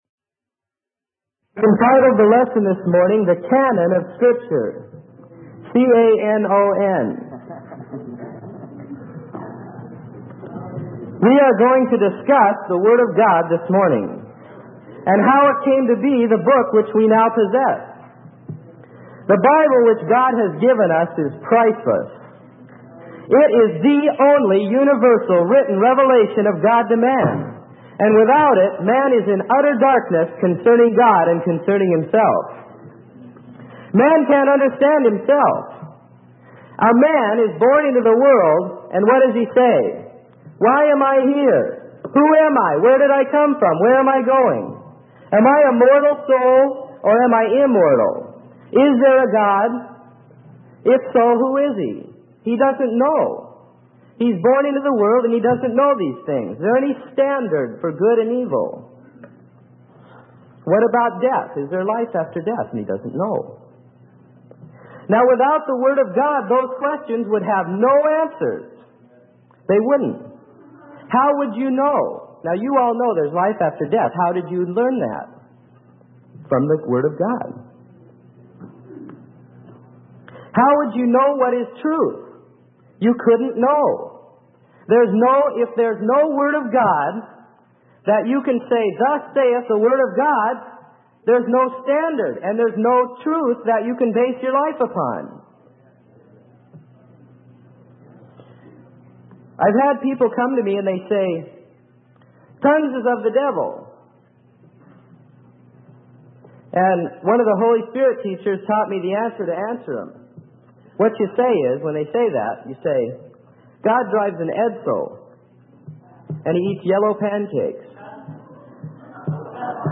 Sermon: The Canon of Scripture-What Are the Inspired Scriptures?